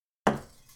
#9 Thump
Bonk Hit Impact Metallic Thump sound effect free sound royalty free Memes